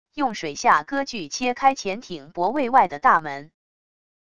用水下割炬切开潜艇泊位外的大门wav音频